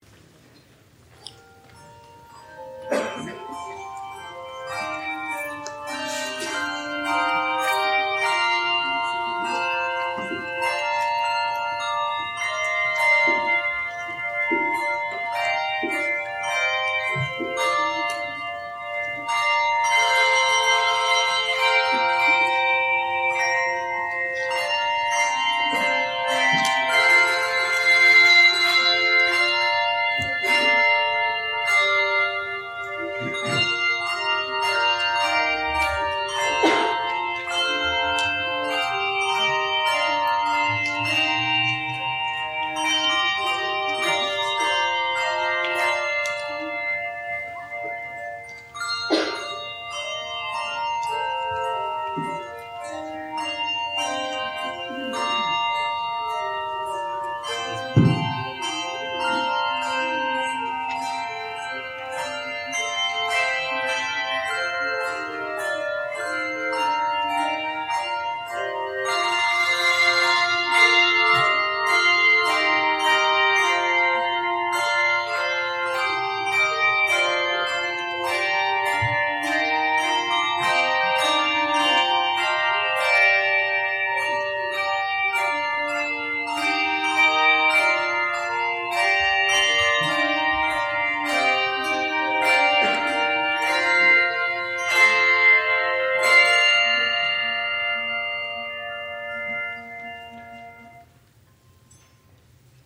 Bell Choir
Bell Choir Performances